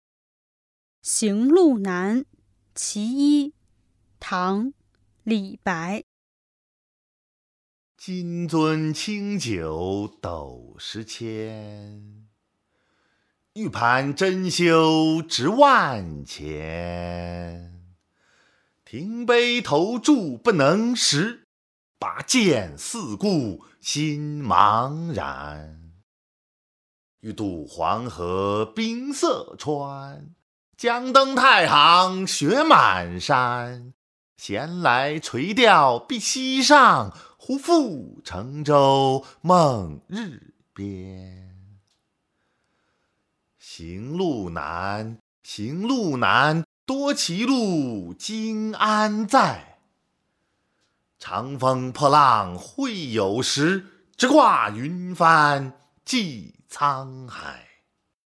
［唐］李白  行路难（其一）（读诵）